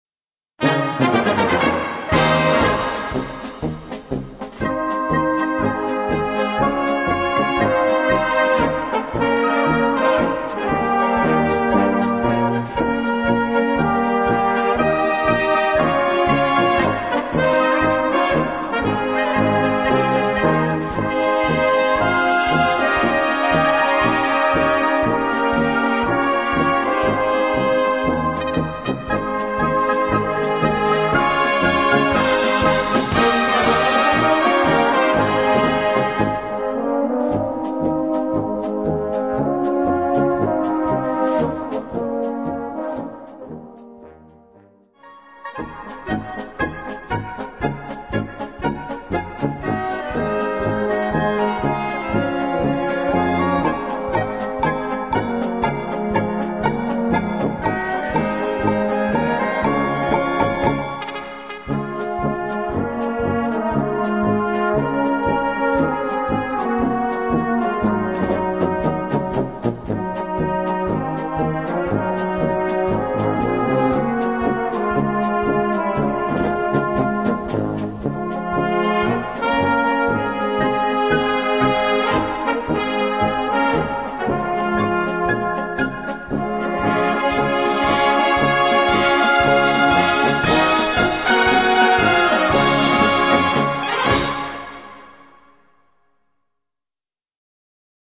Gattung: Polka
Besetzung: Blasorchester
Eine leichte, gut klingende Polka.